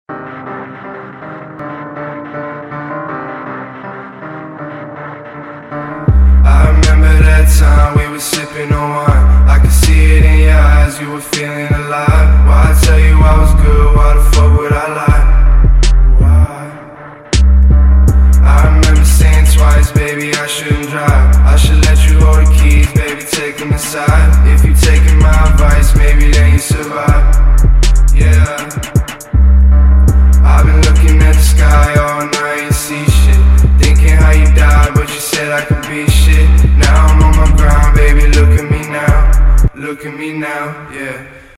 • Качество: 256, Stereo
Хип-хоп
Cloud Rap